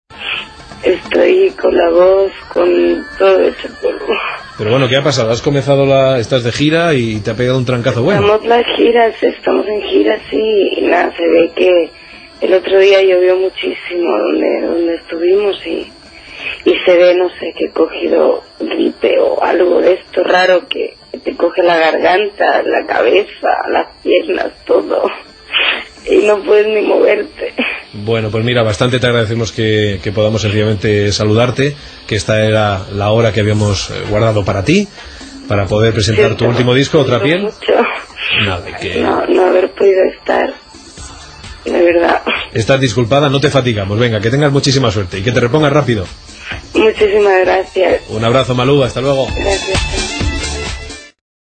Breu entrevista telefònica a la cantant Malú (María Lucía Sánchez) que està griposa i no pot participar al programa
Info-entreteniment